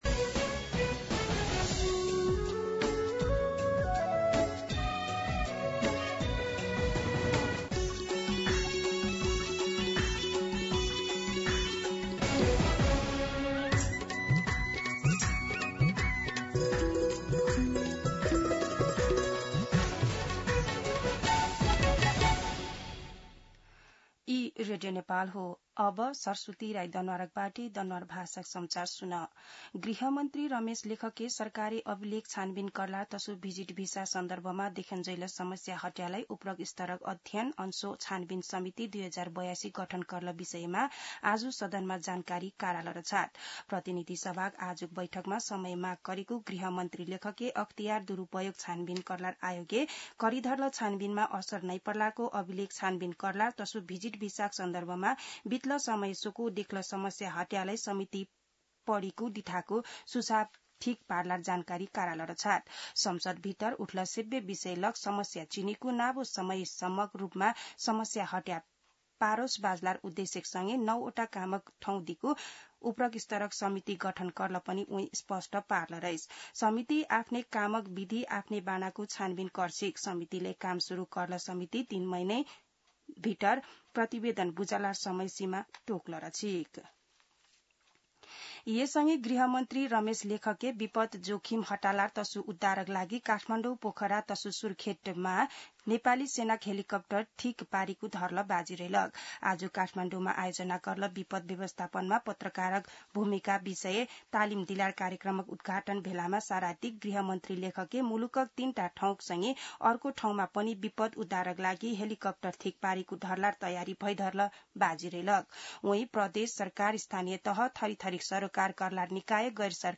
दनुवार भाषामा समाचार : १० असार , २०८२
Danuwar-News-10.mp3